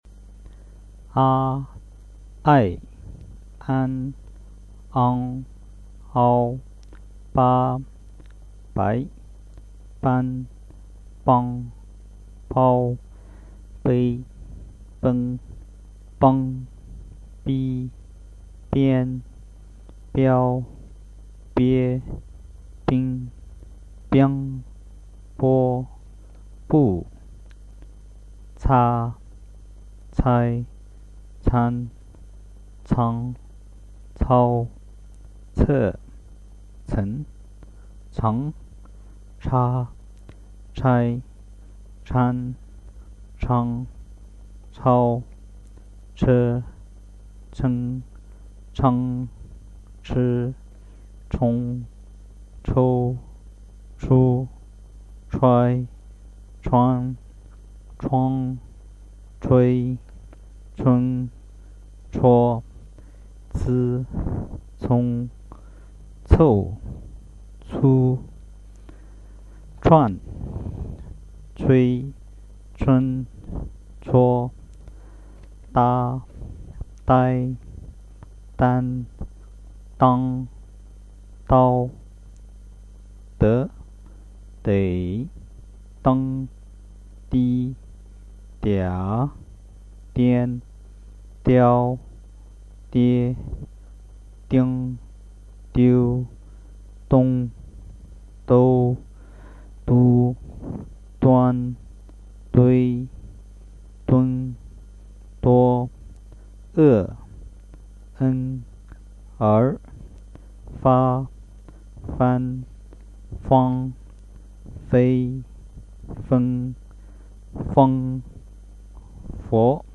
The sounds and description list of the Chinese basic syllables